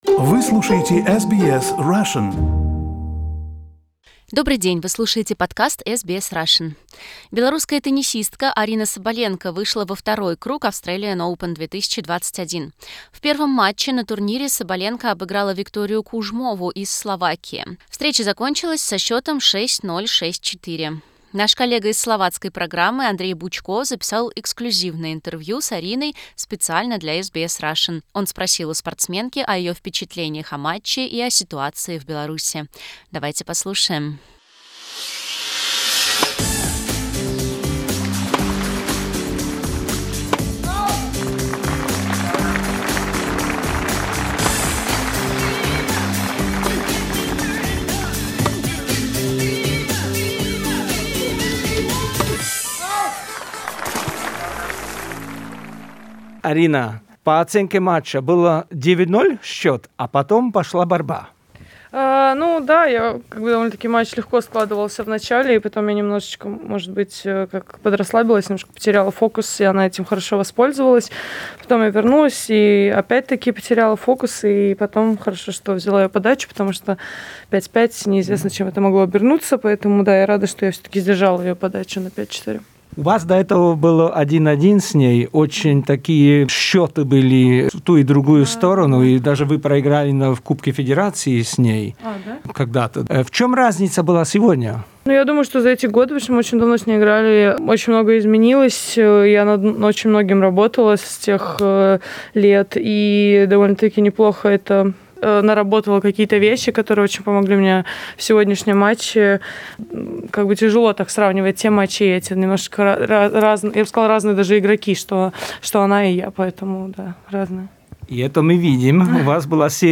Exclusive interview with Aryna Sabalenka